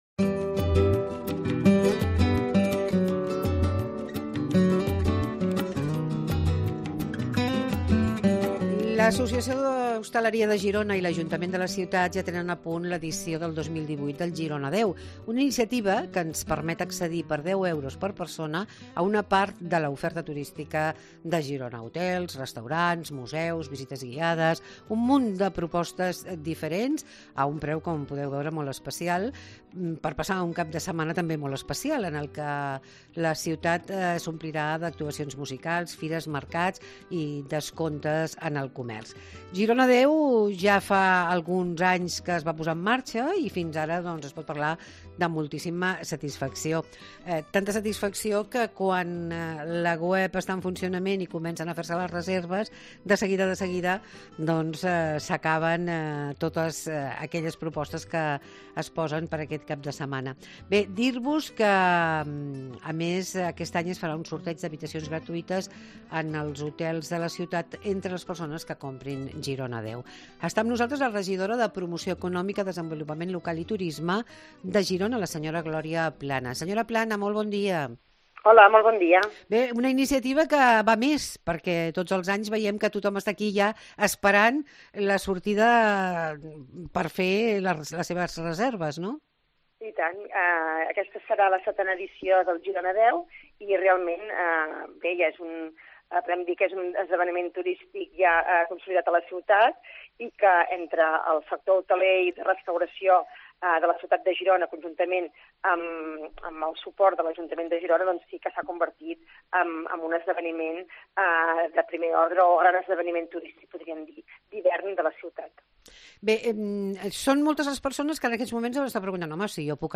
L'Agència Catalana de Turisme ens proposa conèixer Girona10. Parlem amb la regidora de Turisme, Gloria Plana